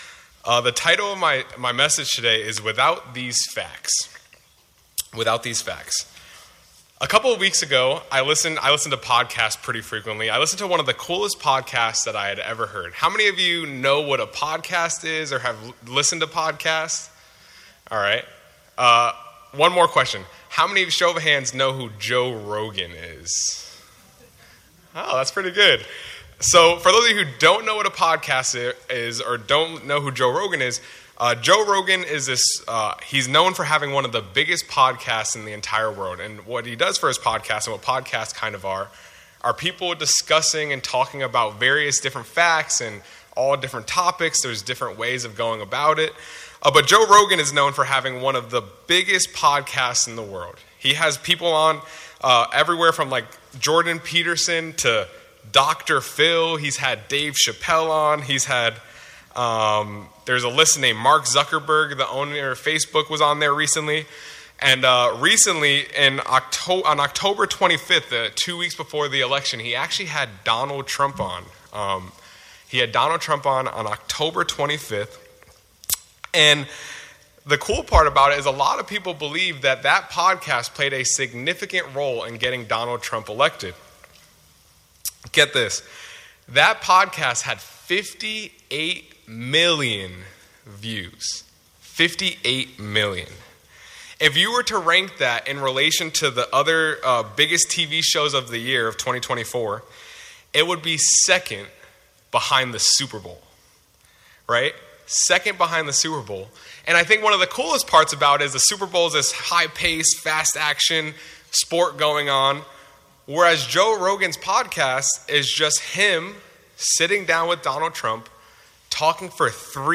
This sermon lays out the case for the miracles that are the life, death, and resurrection of Jesus Christ.